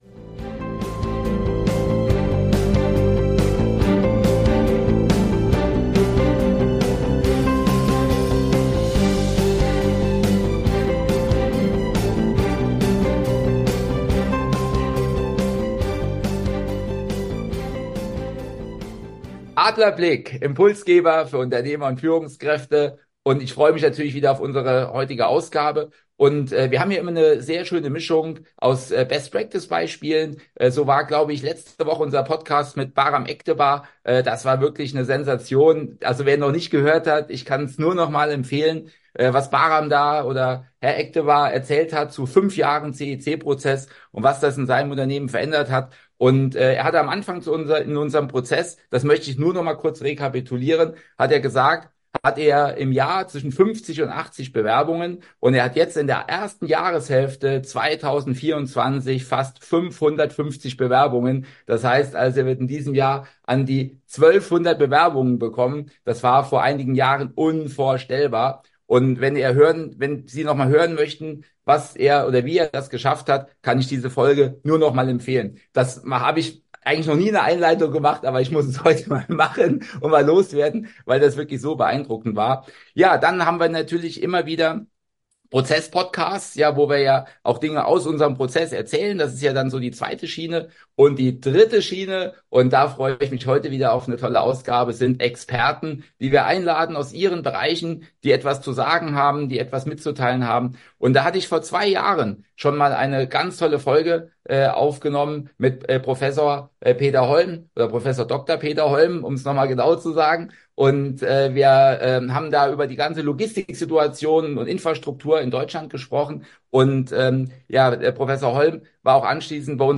In einem tiefgründigen Gespräch beleuchtet er die Hintergründe der Logistikwirtschaft und die daraus resultierenden Auswirkungen im deutschen und europäischen Kontext.